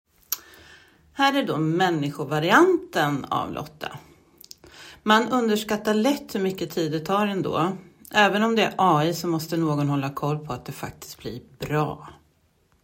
Här kan du lyssna på inspelad människoröst, klonad röst och AI-röst